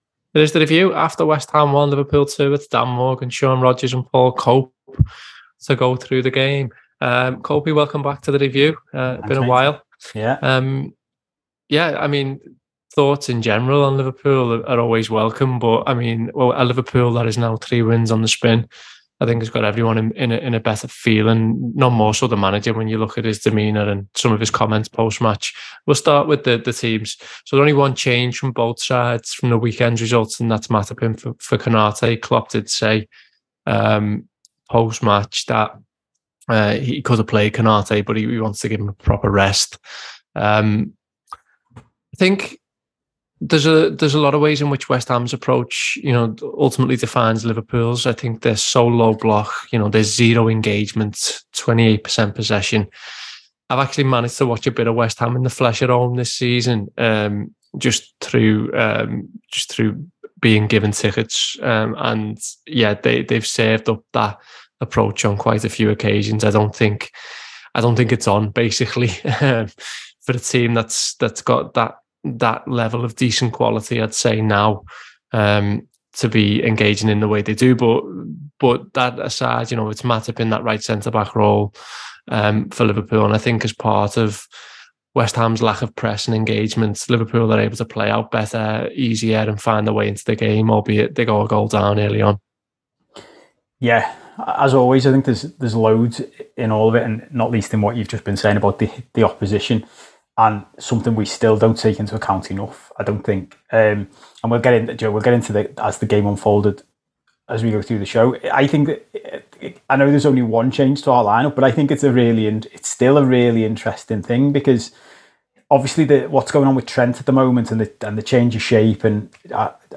Below is a clip from the show – subscribe for more review chat around West Ham 1 Liverpool 2…